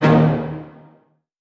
CartoonGamesSoundEffects
Scare_v1_wav.wav